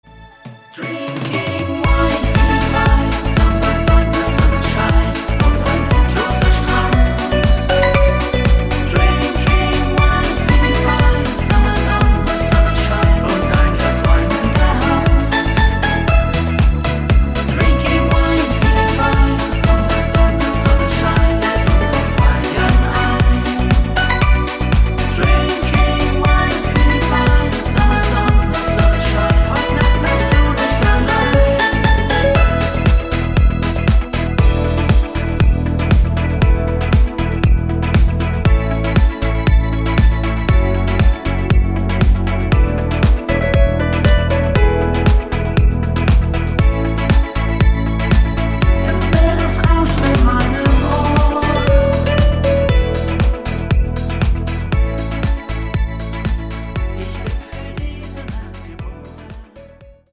Rhythmus  Party Discofox